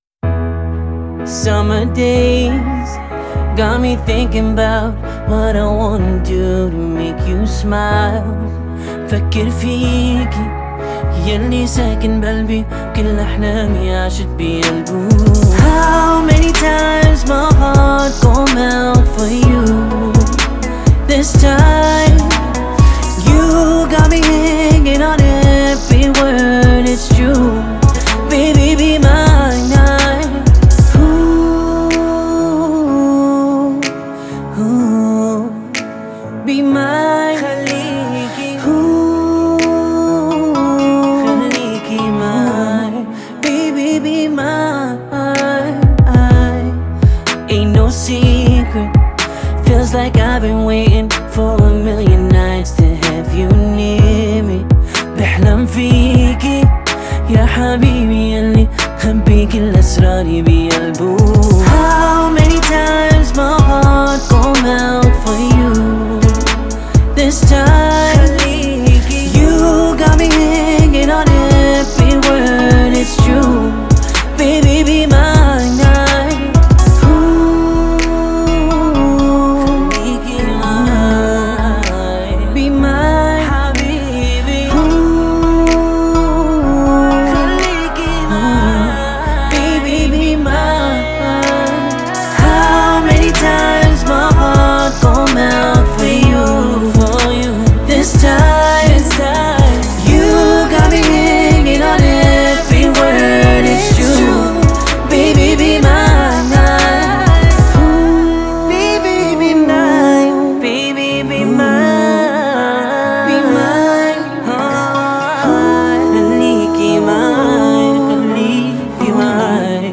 зажигательная поп- и R&B-композиция